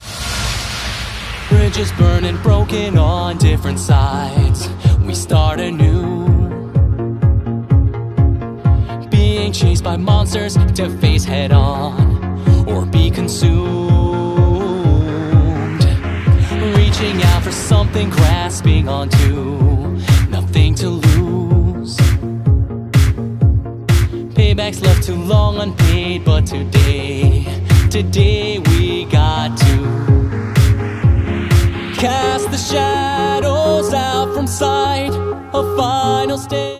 • R&B